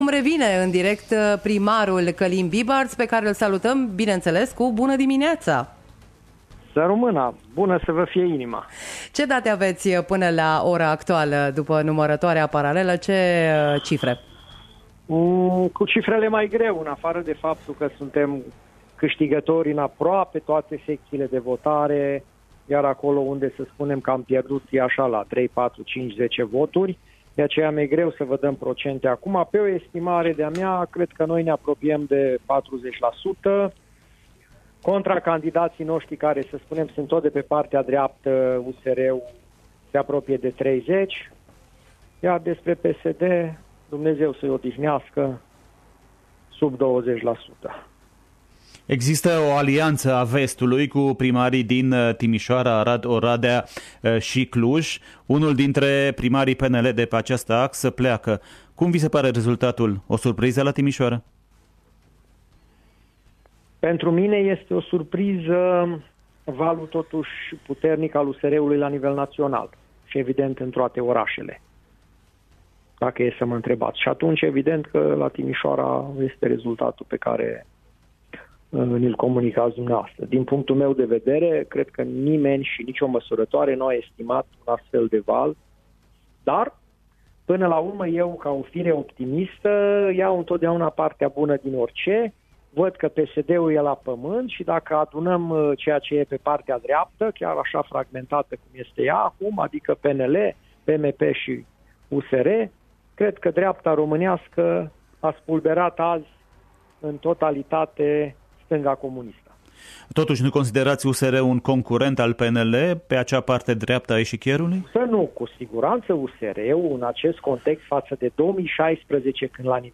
După miezul nopți ia revenit în direct și consideră că rămâne primar la Arad.